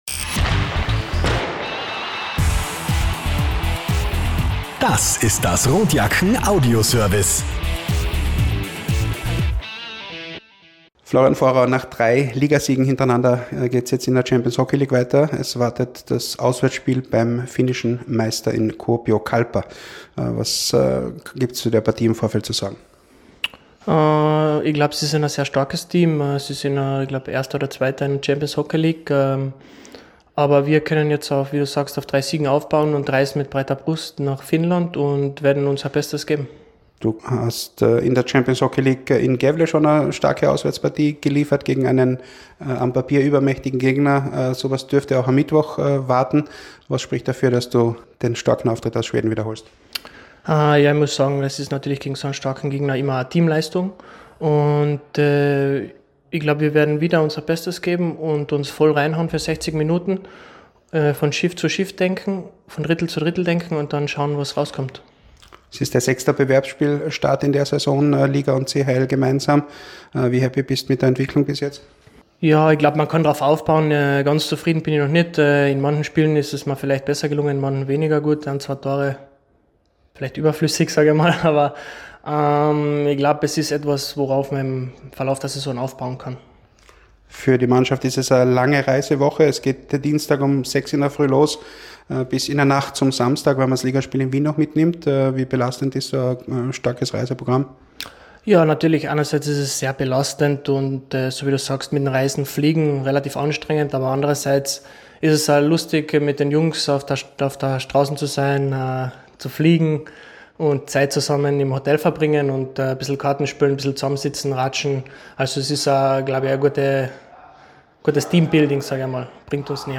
Pre-Game-Kommentar: